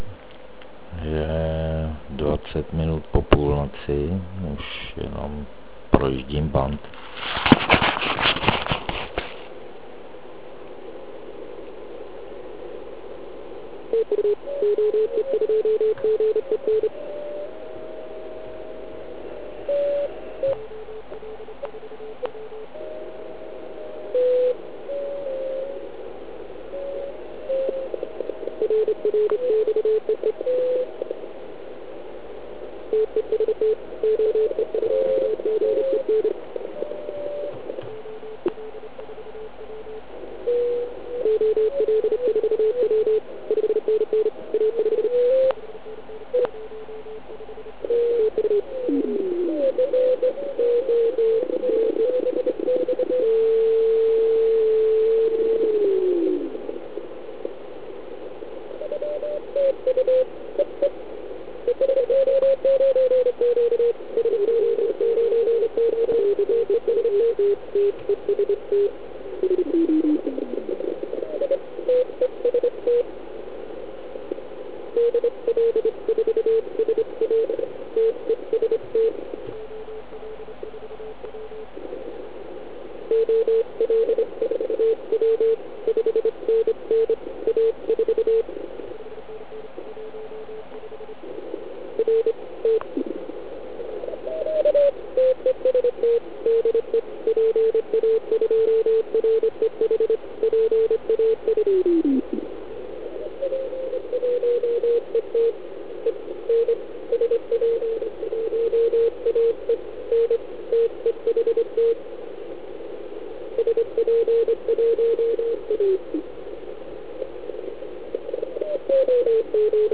Posledn� hodinu z�vodu jsem nahr�l. Nahr�vka je zde: Dalš� pap�rek v "šupl�ku" Vzhledem k m�m "chab�m" ant�n�m pro p�smo 160m, nejsem zas až takov� př�znivec tohoto p�sma.